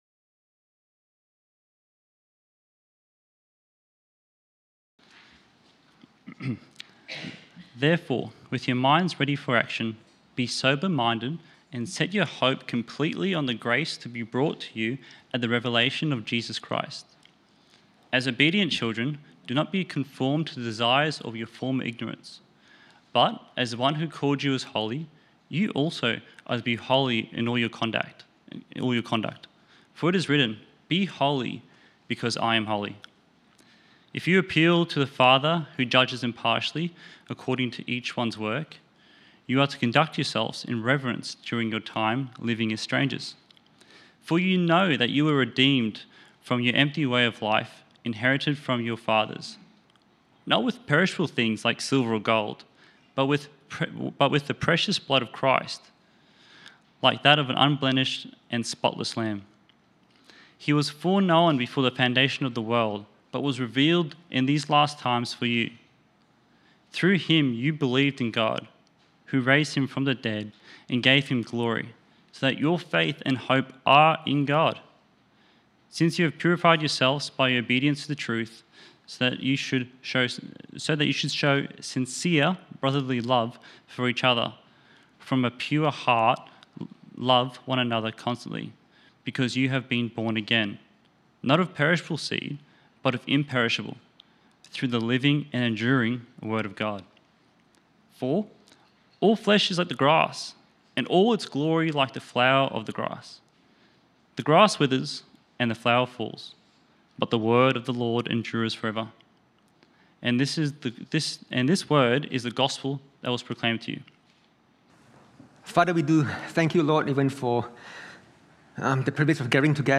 1 Peter 1:13-25 Service Type: Evening Service Our future hope should shape and govern our present living.